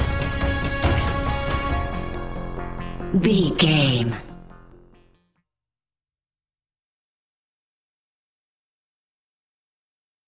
Expanding Granular Suspense Stinger.
In-crescendo
Aeolian/Minor
ominous
haunting
eerie
horror music
Horror Pads
horror piano
Horror Synths